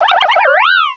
pokeemerald / sound / direct_sound_samples / cries / buneary.aif